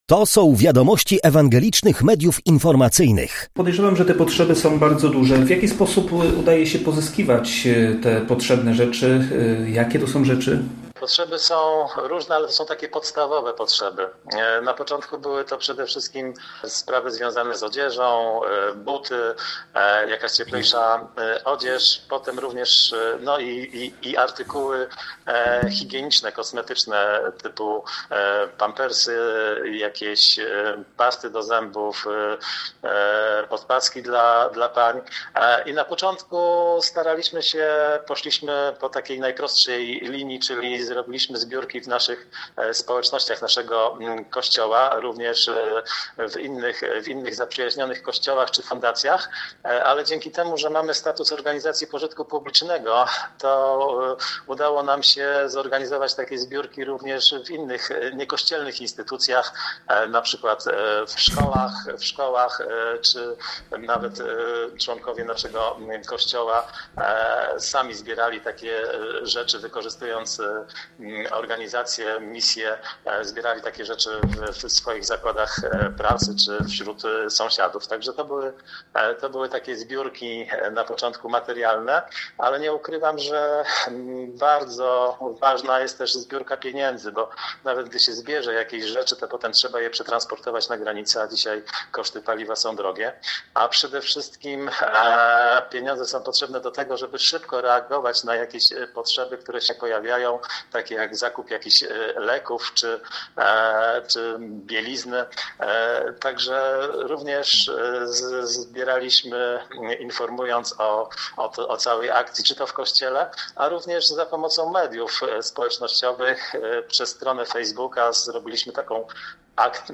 cala-rozmowa.mp3